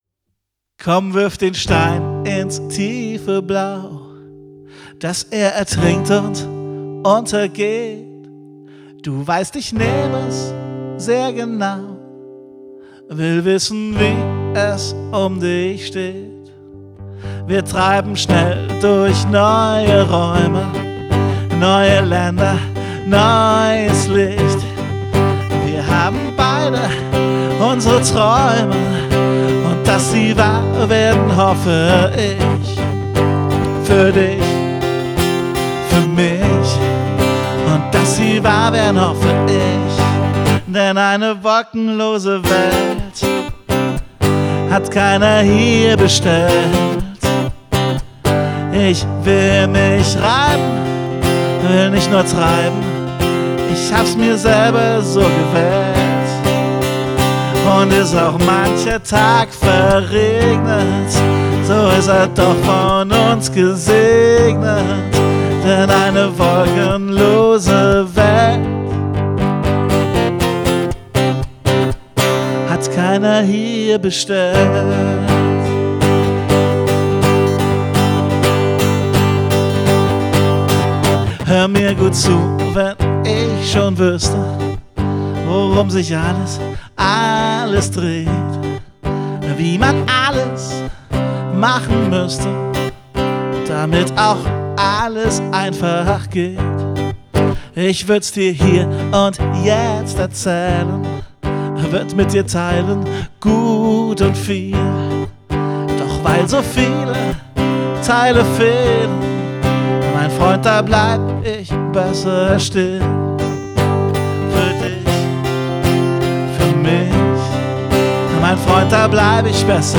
Aufgenommen und gemischt am 29. Juli 2017